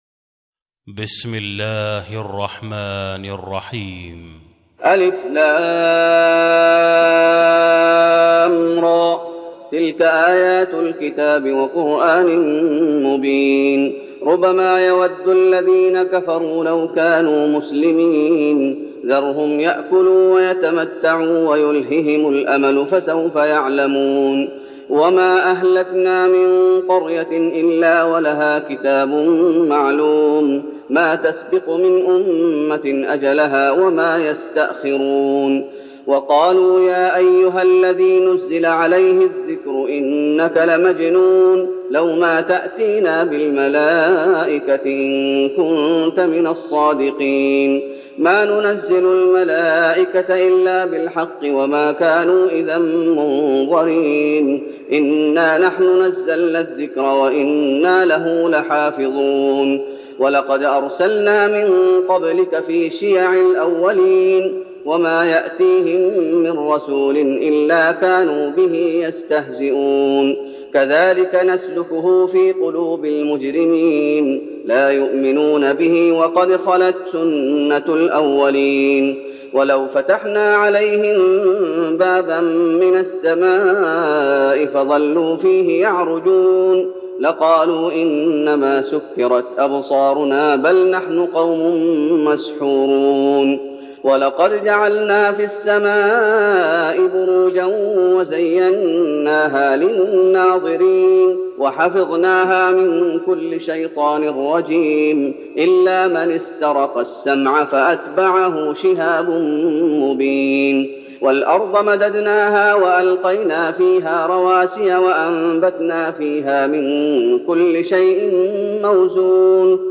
تراويح رمضان 1412هـ من سورة الحجر Taraweeh Ramadan 1412H from Surah Al-Hijr > تراويح الشيخ محمد أيوب بالنبوي 1412 🕌 > التراويح - تلاوات الحرمين